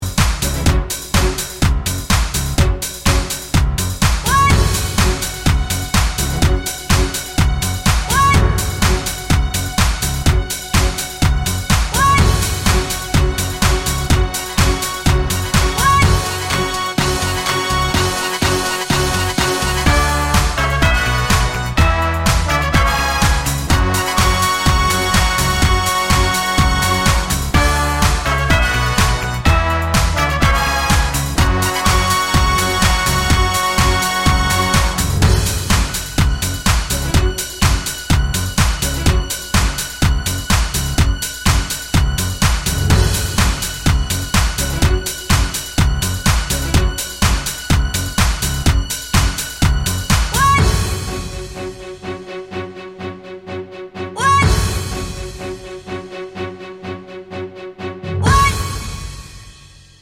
no BV Pop (1990s) 2:21 Buy £1.50